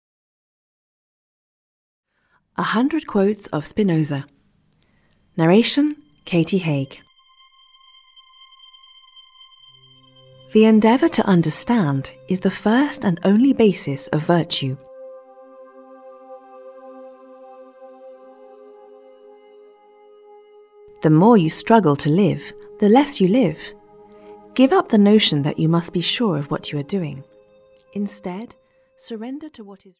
Ukázka z knihy
This audiobook is a collection of a hundred quotes chosen among the most representative of his great works, so that the listener can easily familiarize himself with the thought of one of the greatest European thinkers.